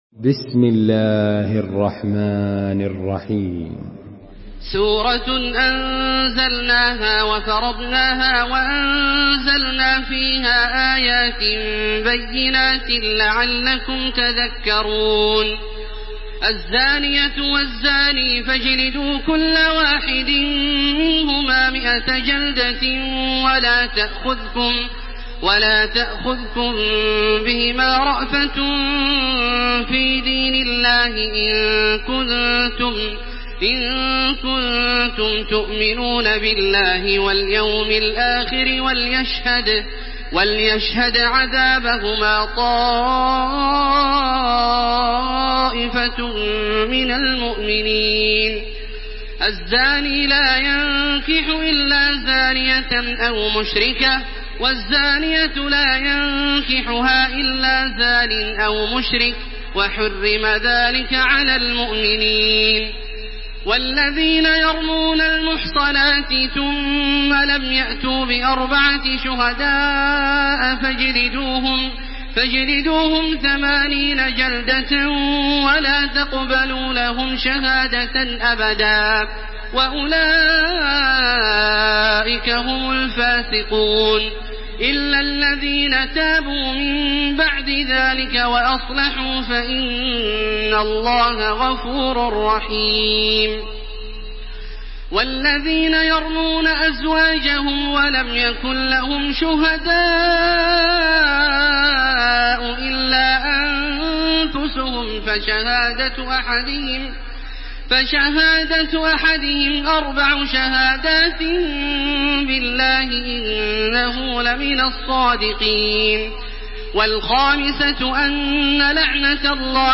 تحميل سورة النور بصوت تراويح الحرم المكي 1429